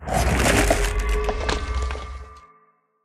Minecraft Version Minecraft Version 1.21.5 Latest Release | Latest Snapshot 1.21.5 / assets / minecraft / sounds / block / respawn_anchor / set_spawn3.ogg Compare With Compare With Latest Release | Latest Snapshot